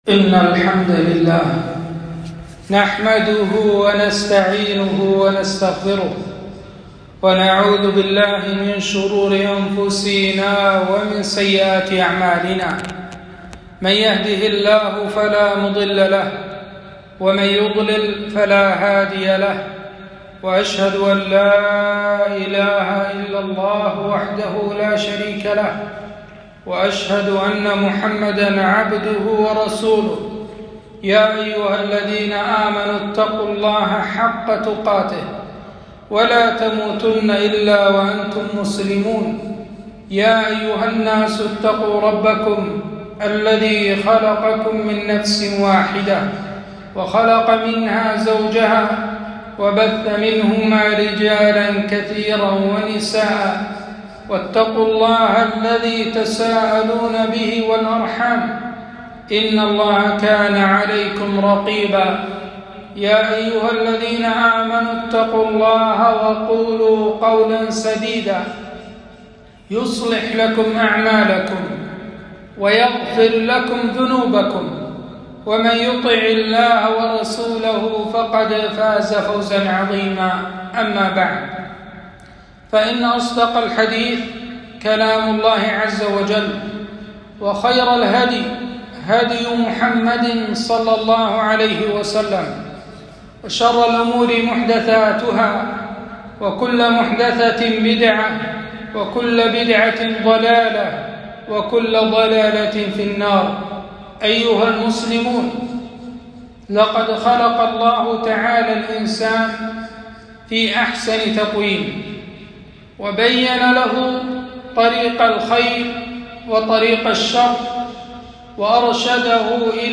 خطبة - علامات محبة الله لعبده